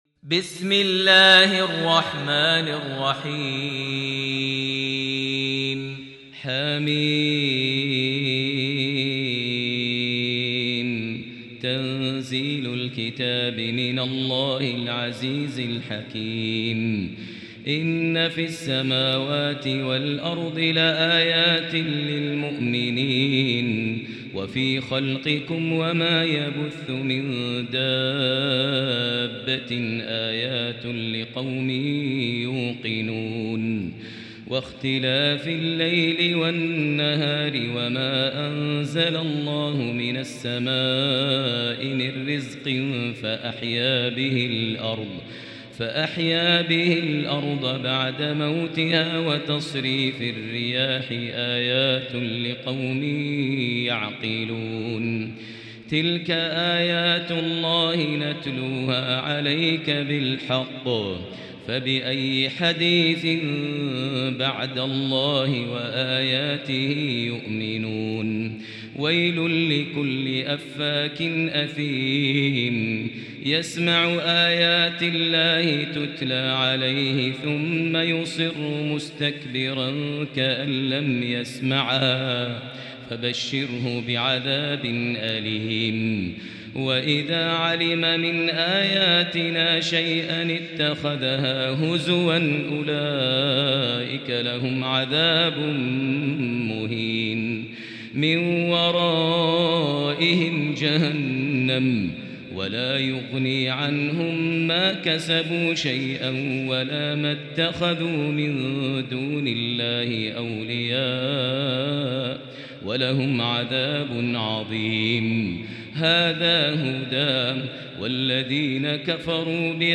المكان: المسجد الحرام الشيخ: فضيلة الشيخ ماهر المعيقلي فضيلة الشيخ ماهر المعيقلي الجاثية